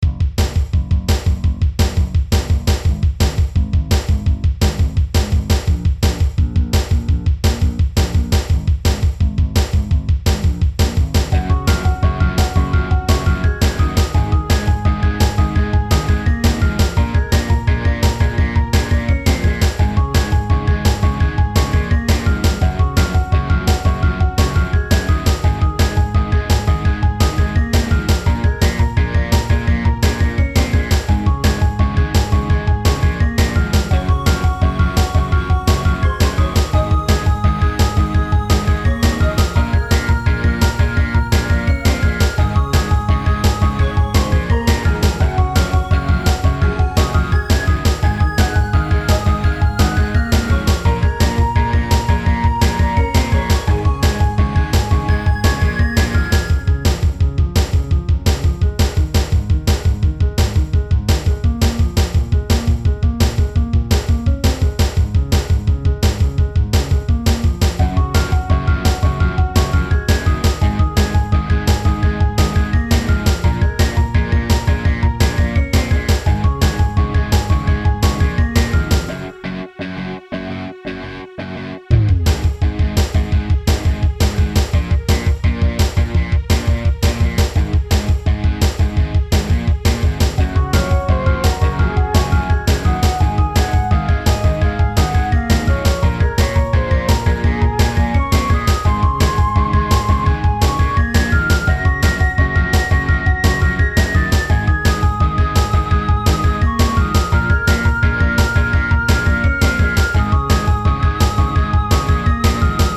• Music requires/does smooth looping